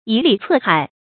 以蠡测海 yǐ lí cè hǎi
以蠡测海发音
成语注音ㄧˇ ㄌㄧˊ ㄘㄜˋ ㄏㄞˇ
成语正音蠡，不能读作“yuán”或“lǐ”。